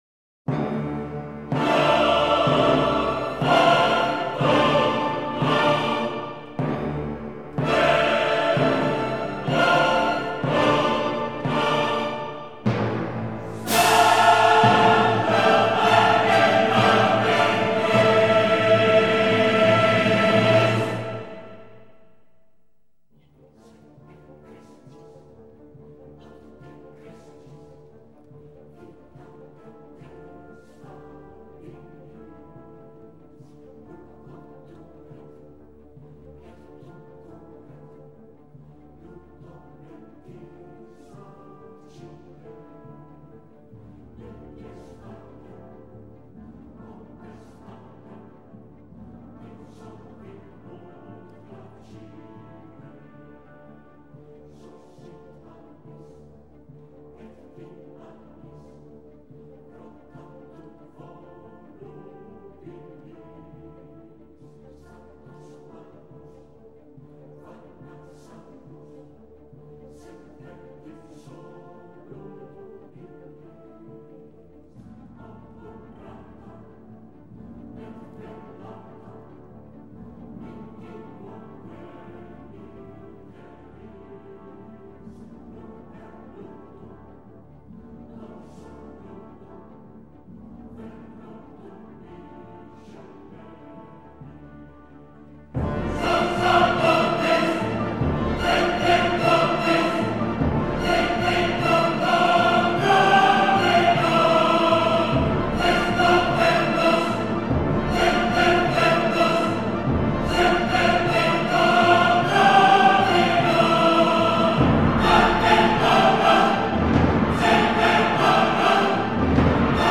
soprano
baryton
音樂類型：古典音樂